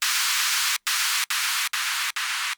Pseudorauschen > 1000 Hz (0°, 15°, 30°, 45°, 60°; MP3 42 kB)